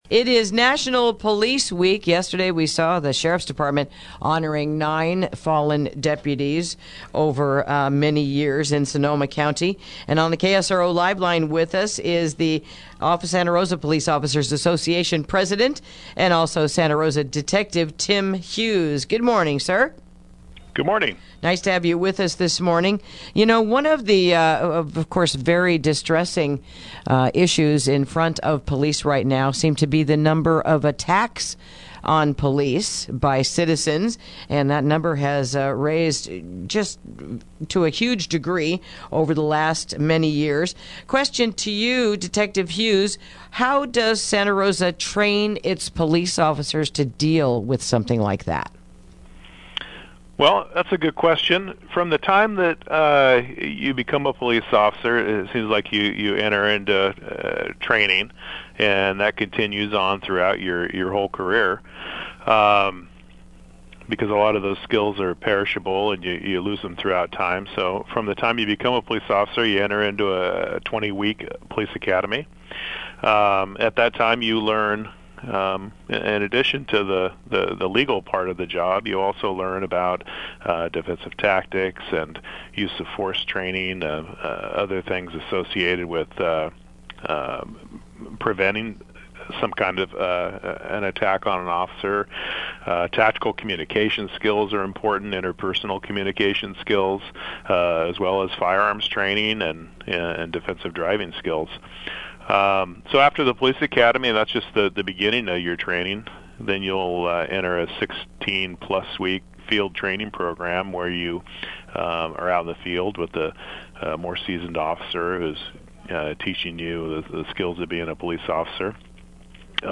Interview: It's National Police Week | KSRO 103.5FM 96.9FM & 1350AM